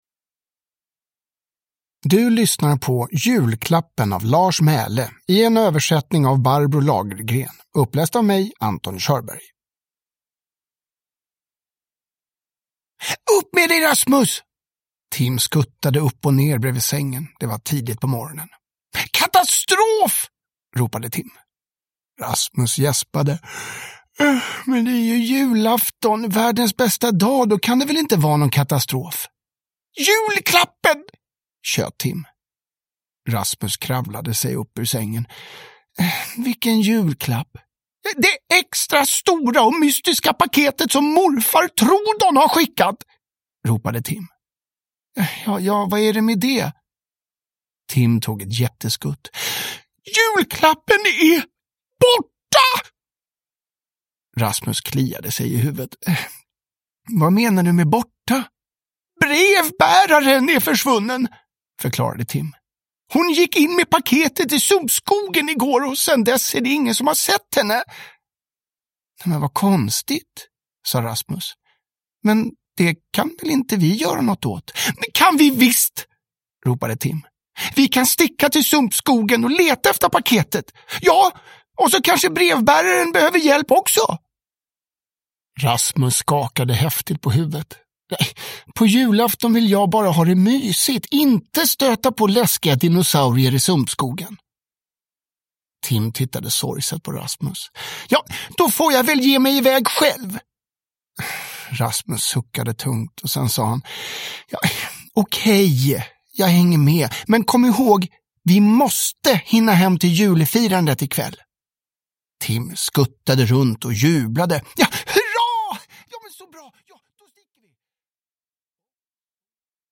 Julklappen – Ljudbok